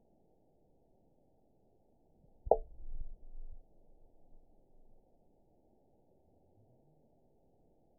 event 920457 date 03/26/24 time 18:23:35 GMT (1 year, 1 month ago) score 9.51 location TSS-AB01 detected by nrw target species NRW annotations +NRW Spectrogram: Frequency (kHz) vs. Time (s) audio not available .wav